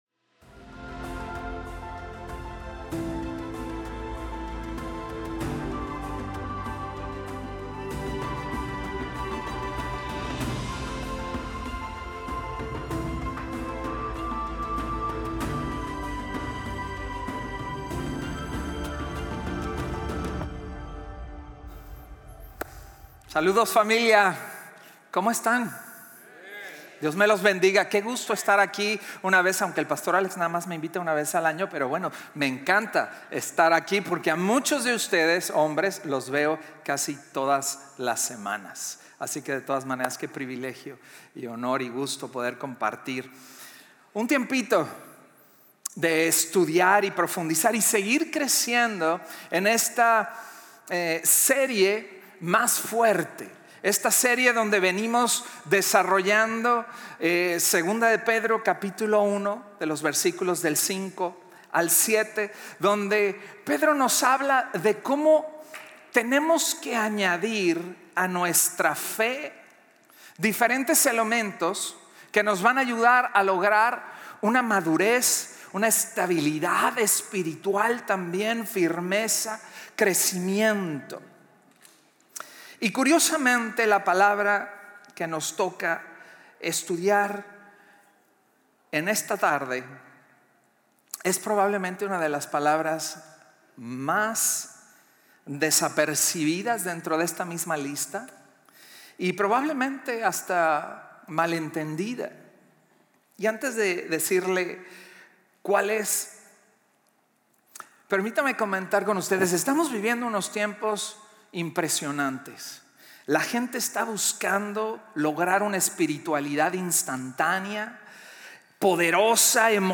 Un mensaje de la serie "Decepción."